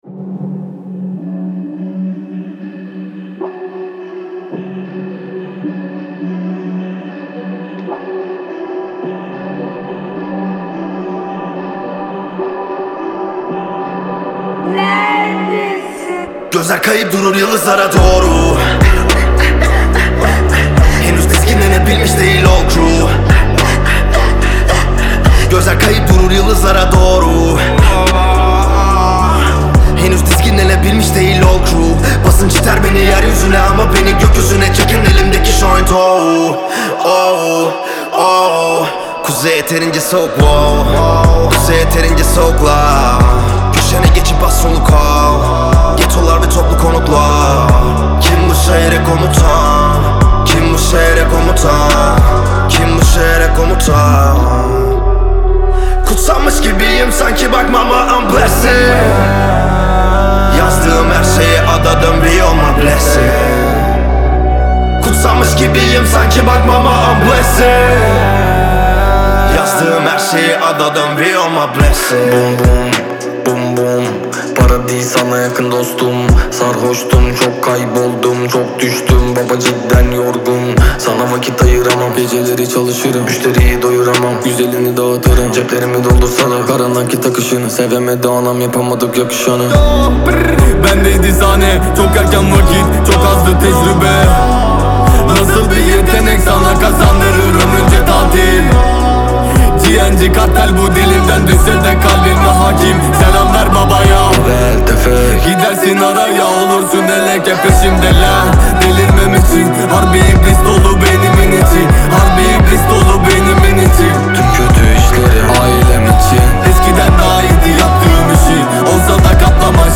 Трек размещён в разделе Рэп и хип-хоп / Турецкая музыка.